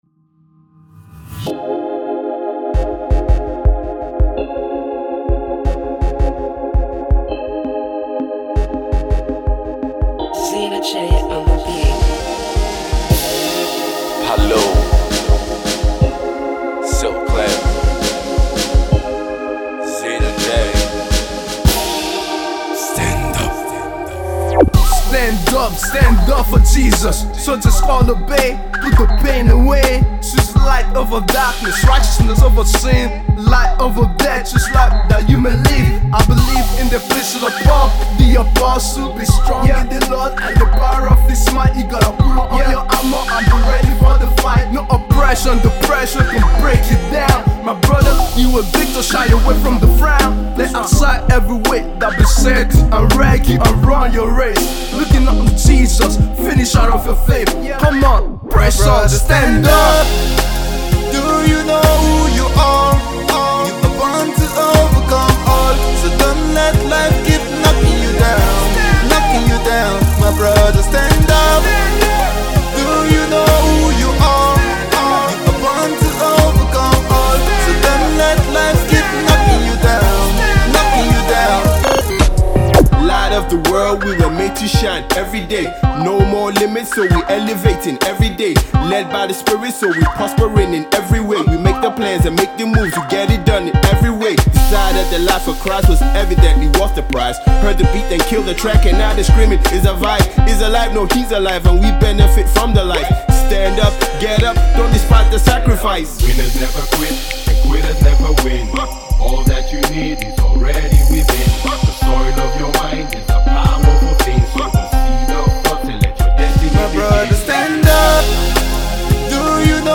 HipHop/Rap jam